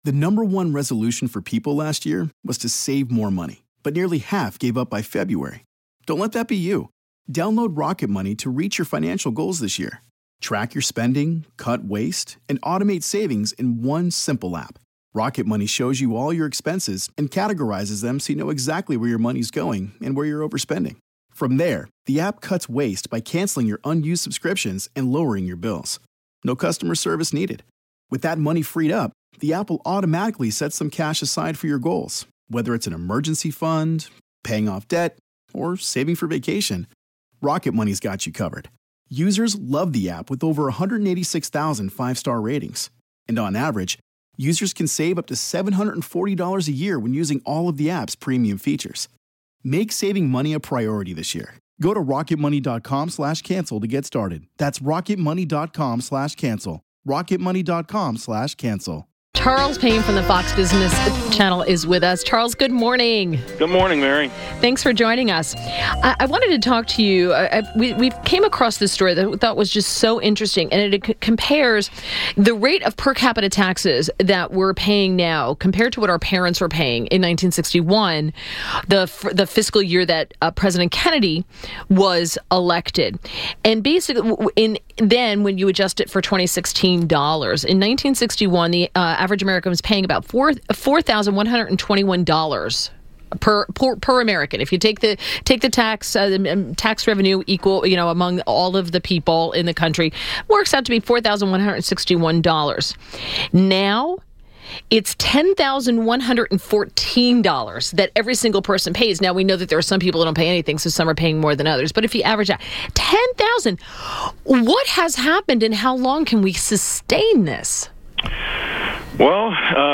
WMAL Interview - CHARLES PAYNE 06.01.17